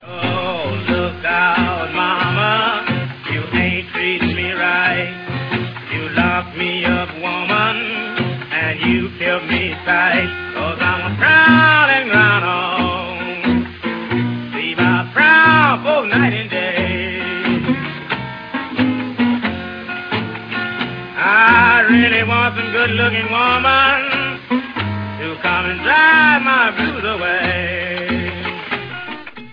вокал, стиральная доска
гитара